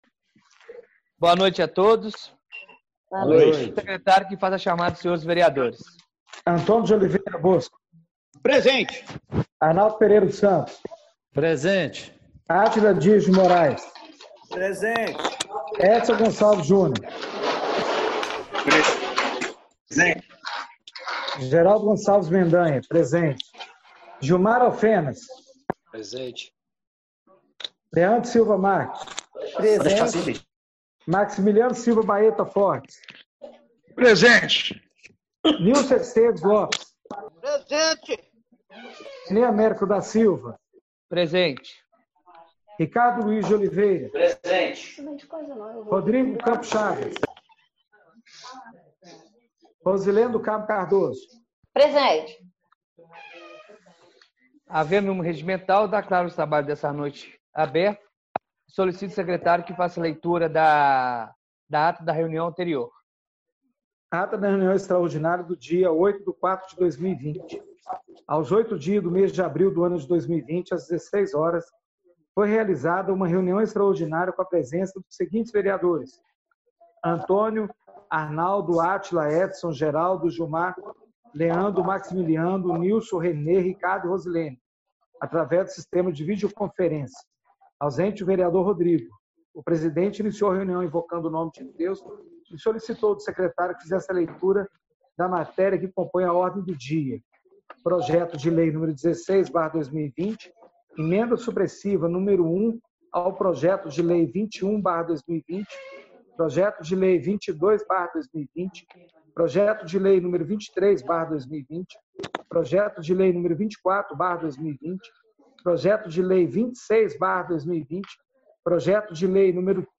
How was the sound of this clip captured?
Reunião Ordinária do dia 13/04/2020